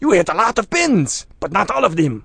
mp_youhitlotsofpins.wav